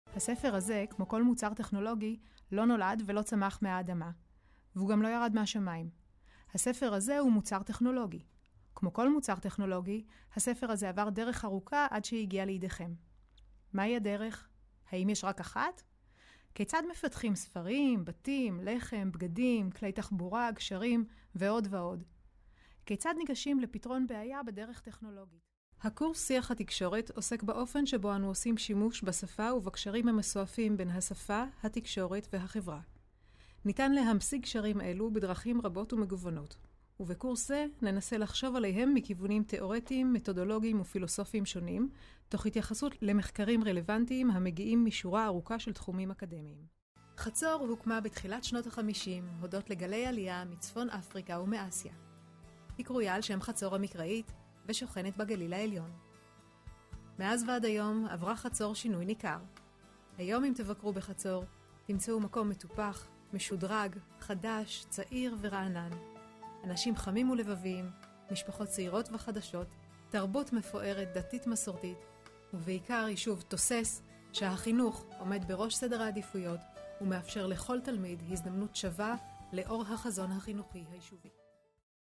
Professionelle Sprecher und Sprecherinnen
Weiblich